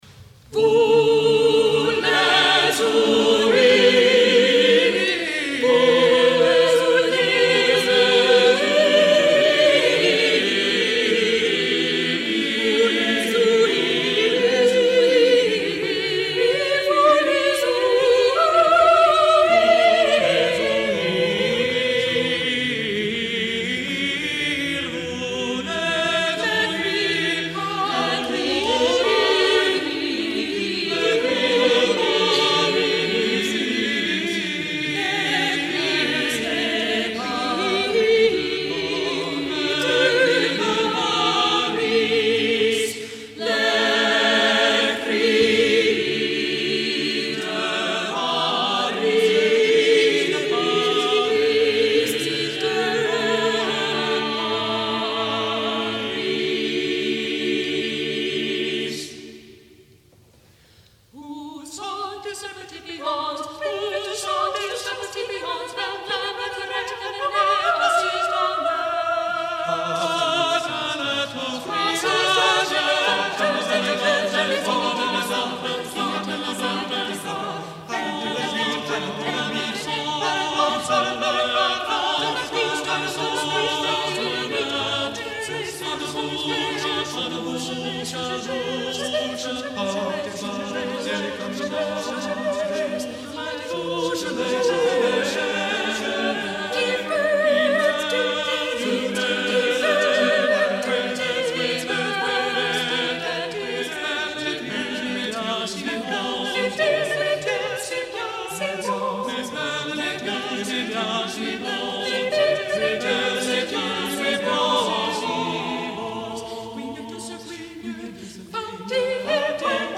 | Vocal ensemble 'Sounds of the City' 1977